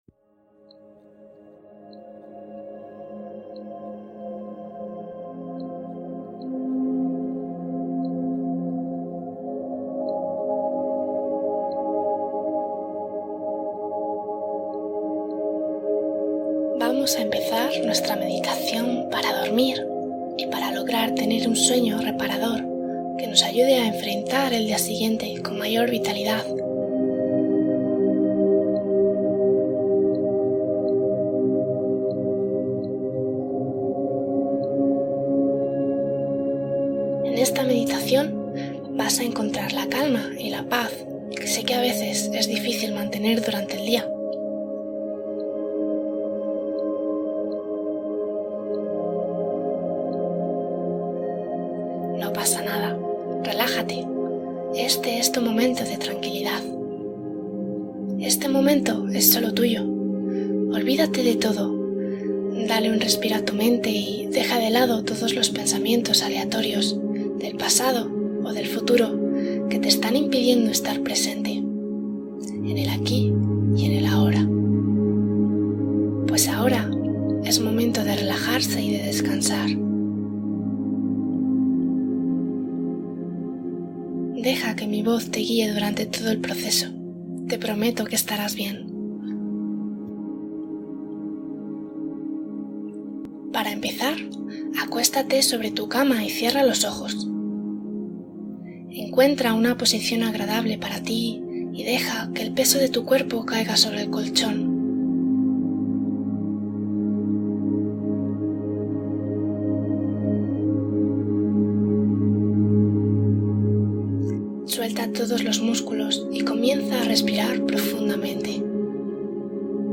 La casa de los sueños | Relajación antes de dormir